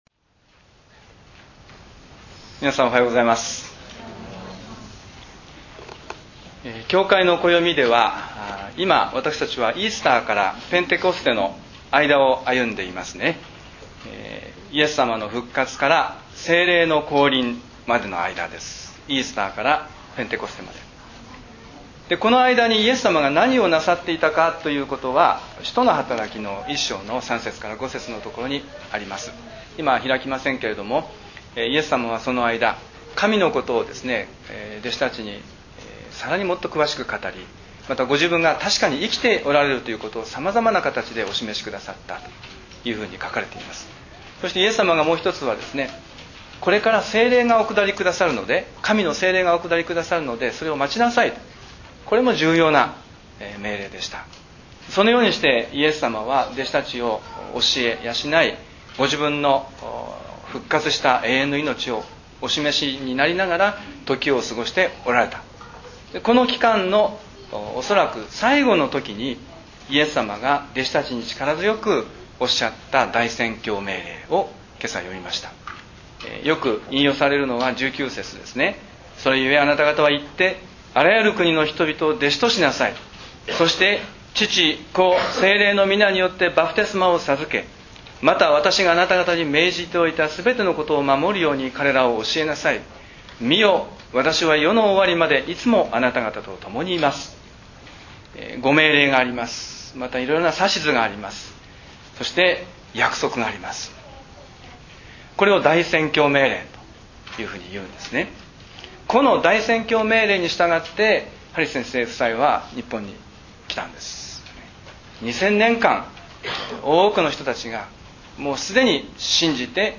礼拝宣教録音－大宣教命令を聞いた人々